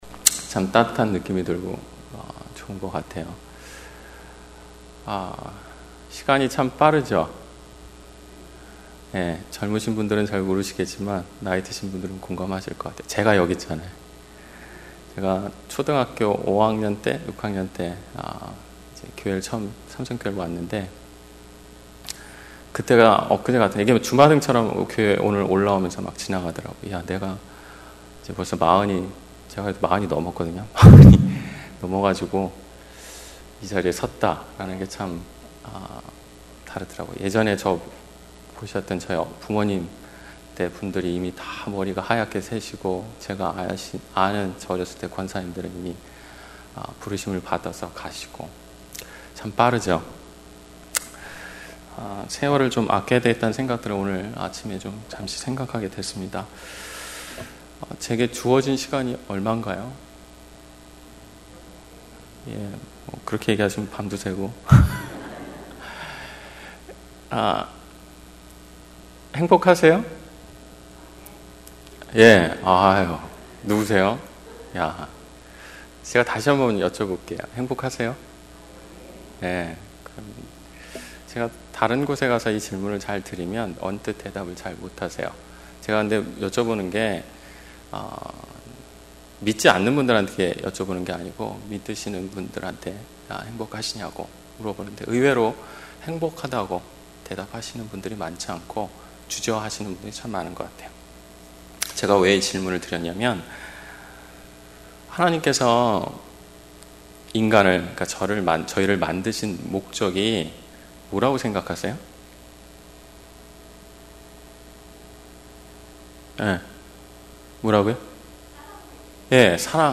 특별집회 - 마가복음 12장 30-32절